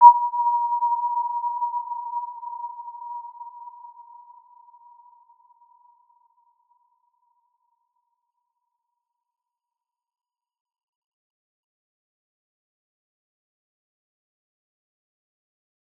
Warm-Bounce-B5-p.wav